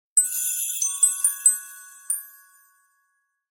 Cell Phone Notification Sounds in MP3 Format
Christmas Drillings